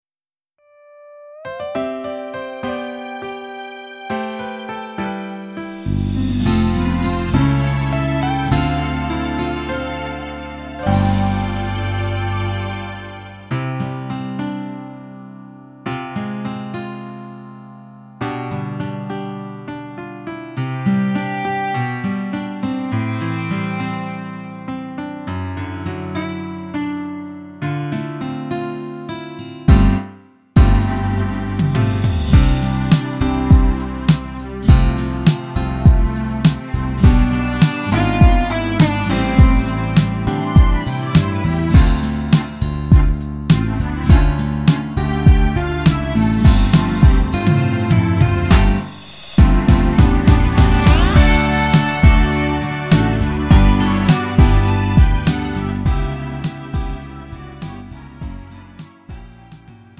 음정 원키 3:55
장르 가요 구분 Lite MR